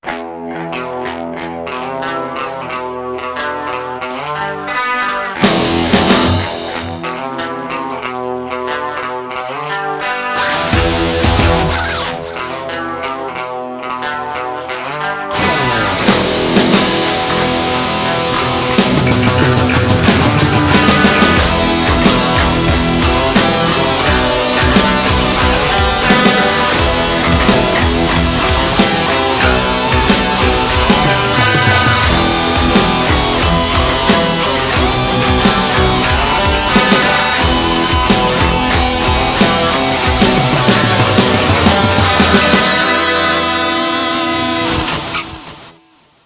After jamming it on the nylon string I ran downstairs to record it. In two sessions during the day, I fleshed it out; getting the guitars almost precisely how I wanted them.
I like the sound, the drums aren't too bad and it's got a fun theme.